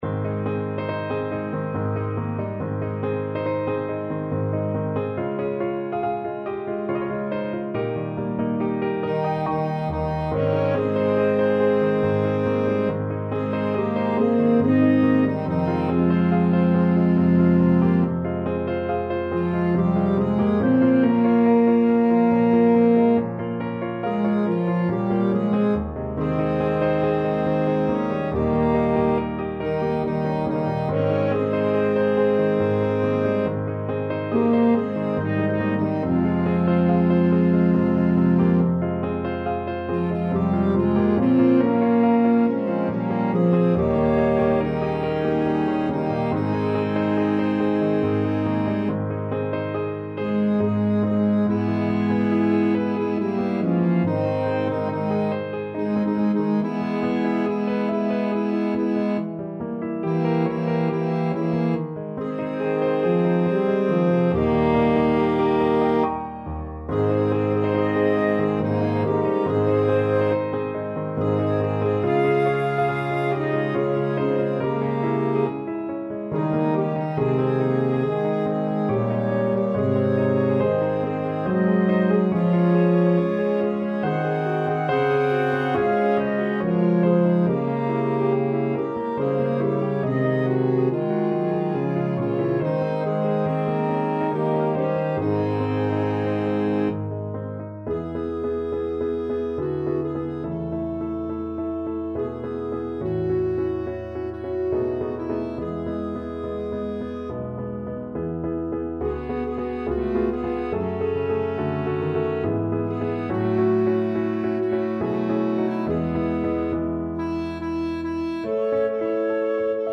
SATB mixed choir and piano
世俗音樂